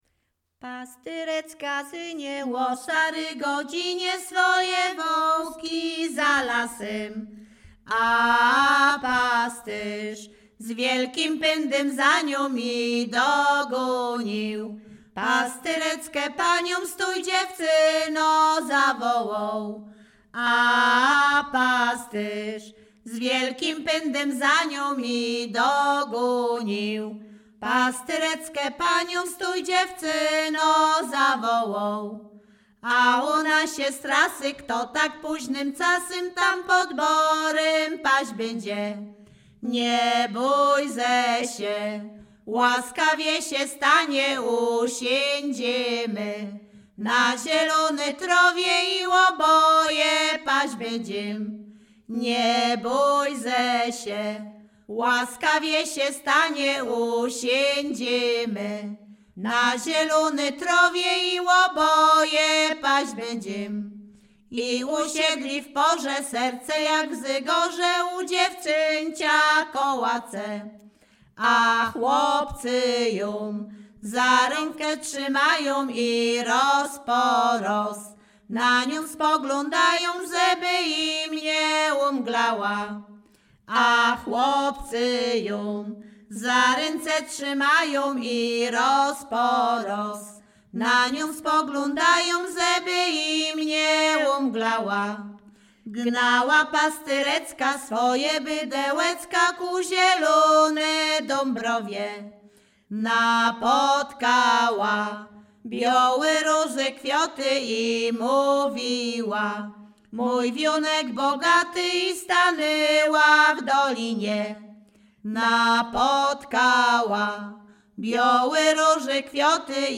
Śpiewaczki z Chojnego
Sieradzkie
województwo łódzkie, powiat sieradzki, gmina Sieradz, wieś Chojne
liryczne miłosne